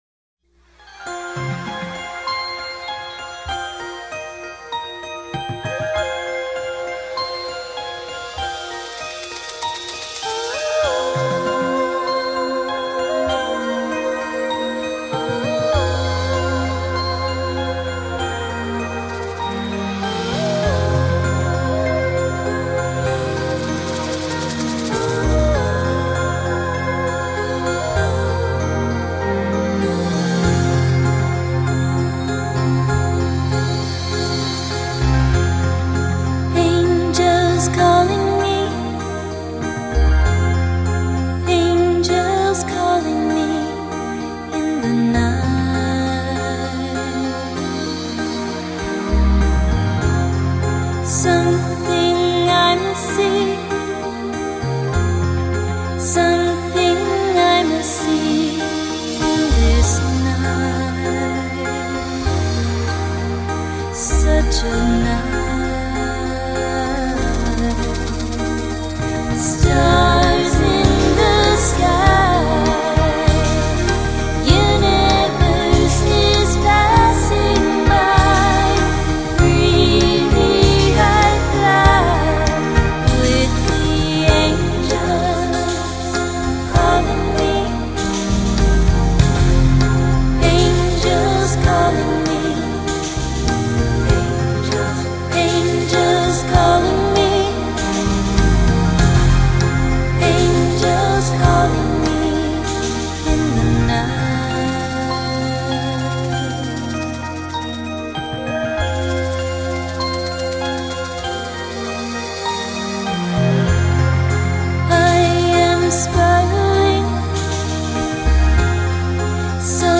演唱歌手：新世纪音乐